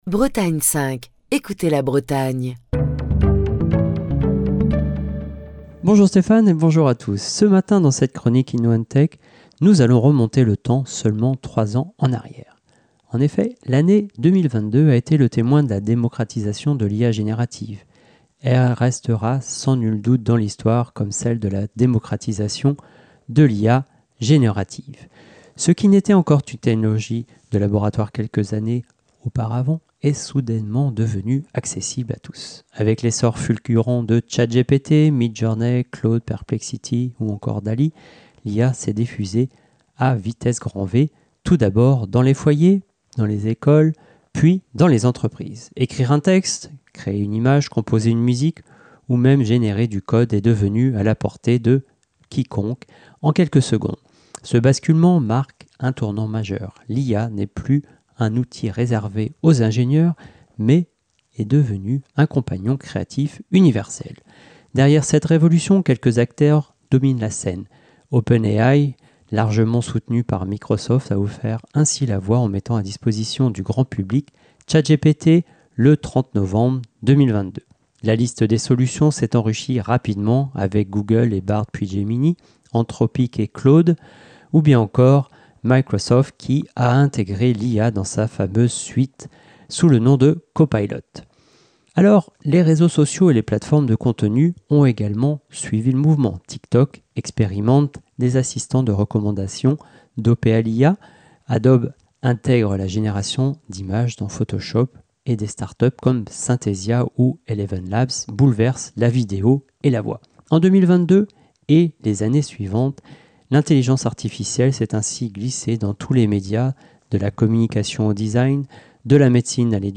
Chronique du 30 octobre 2025.